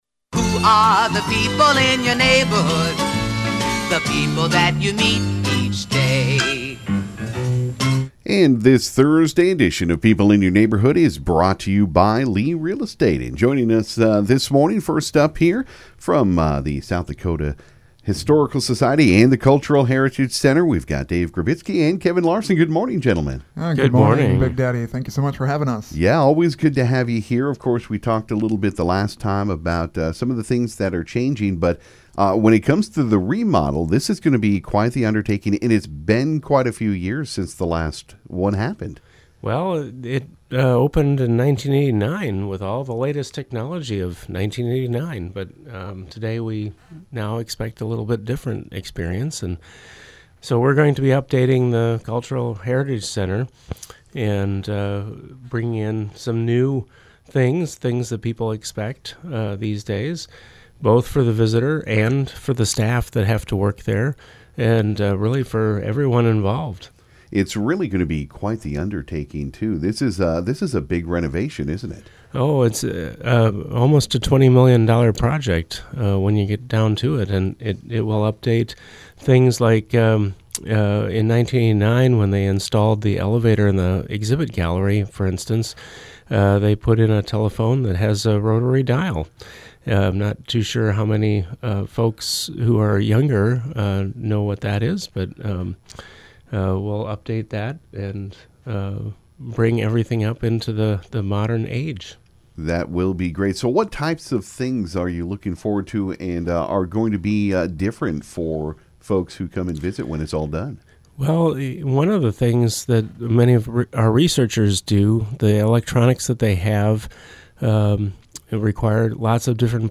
Also Ft. Pierre Mayor Gloria Hanson was in to update us on what’s happening in Ft. Pierre.